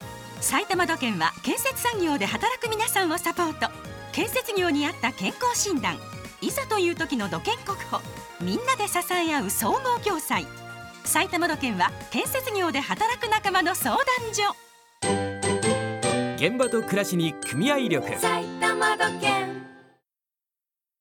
埼玉土建ラジオCMアーカイブ